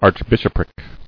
[arch·bish·op·ric]